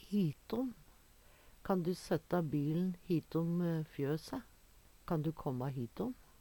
hitom - Numedalsmål (en-US)